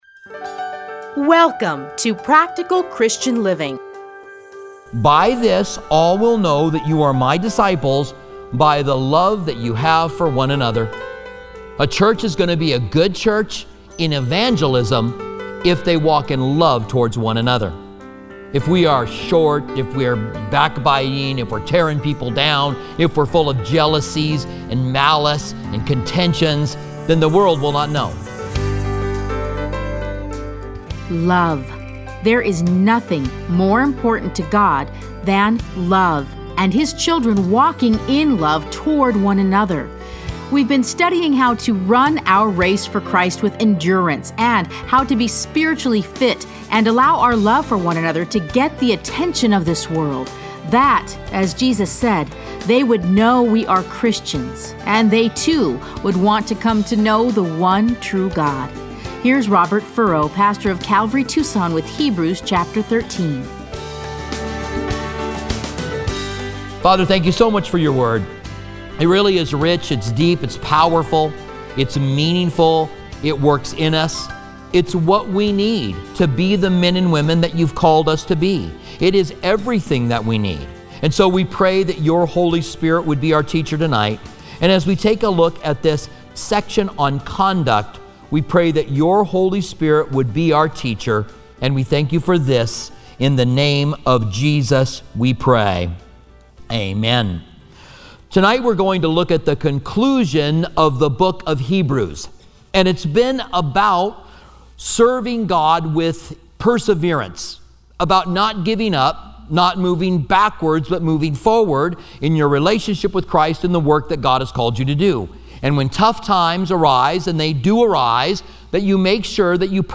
teachings are edited into 30-minute radio programs titled Practical Christian Living. Listen to a teaching from Hebrews 13:1-25.